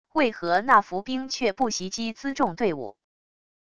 为何那伏兵却不袭击辎重队伍wav音频生成系统WAV Audio Player